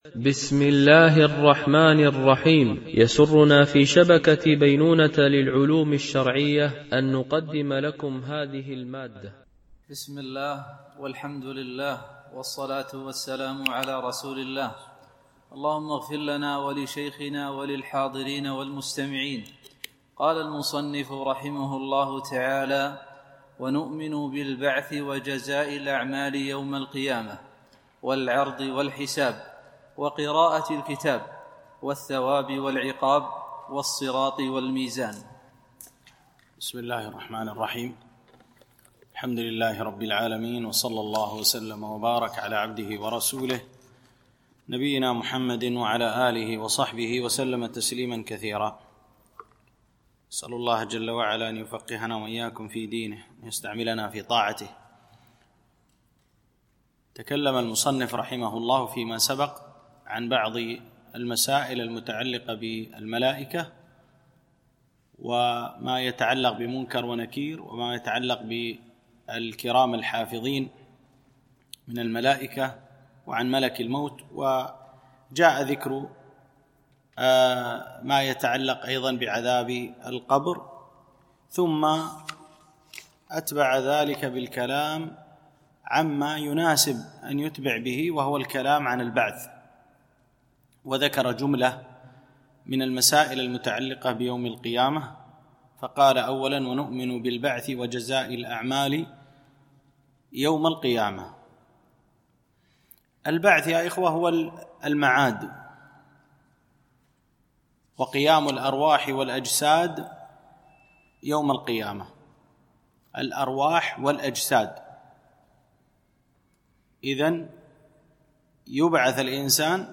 مباحث إيمانية - الدرس 12
MP3 Mono 44kHz 64Kbps (CBR)